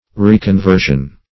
\Re`con*ver"sion\ (-v?r"sh?n), n. A second conversion.